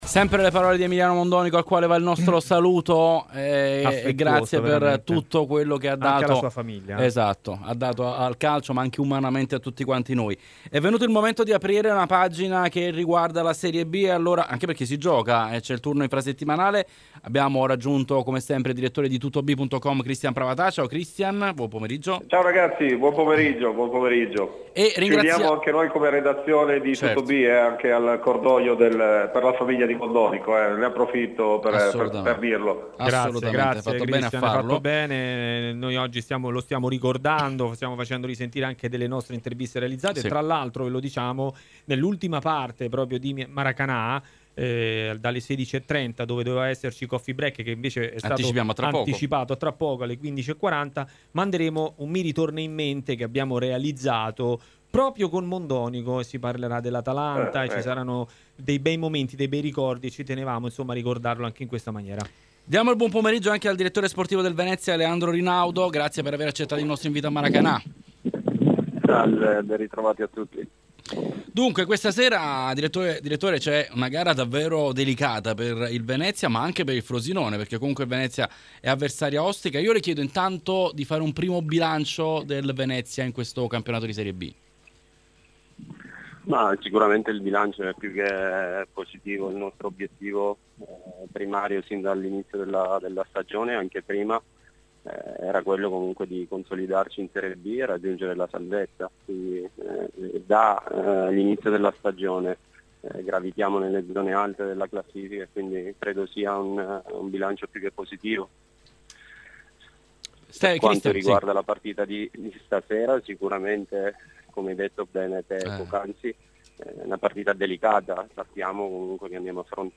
registrazione di TMW Radio